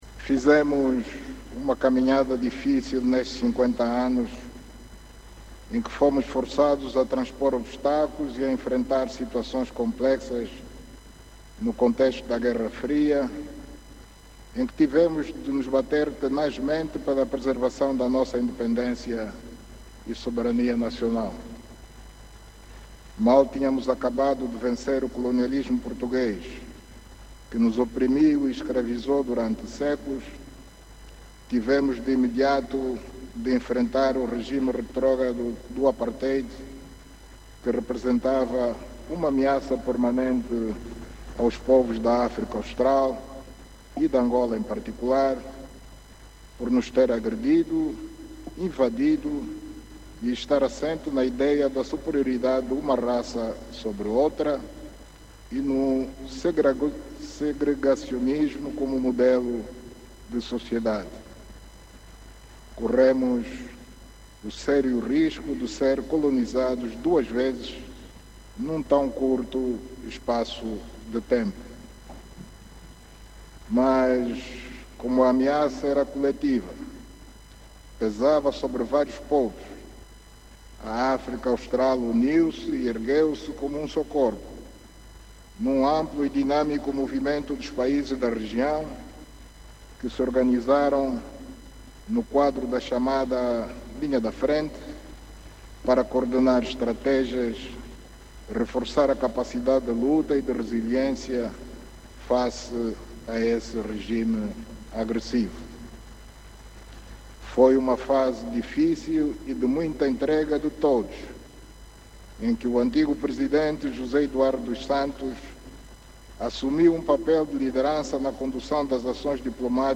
O Chefe de Estado fez estes pronunciamentos durante o seu discurso à Nação, na Praça da República, em Luanda, onde decorre o acto central das comemorações dos 50 anos da Independência Nacional.